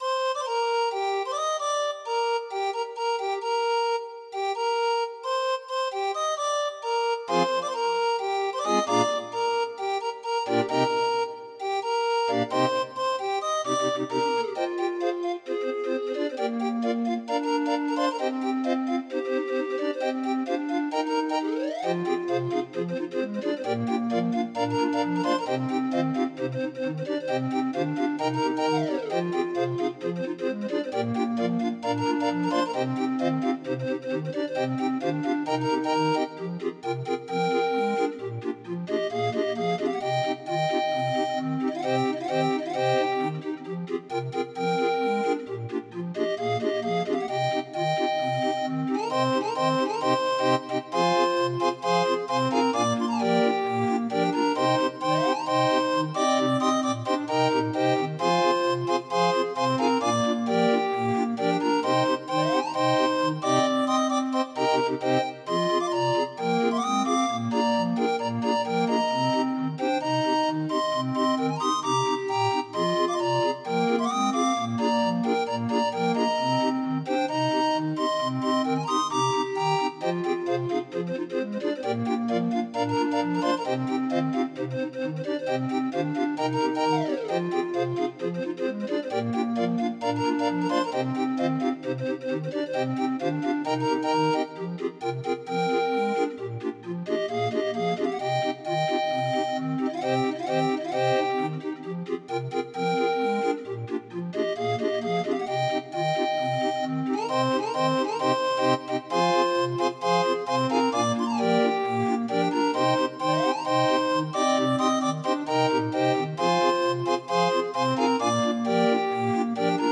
Demo of 31 note MIDI file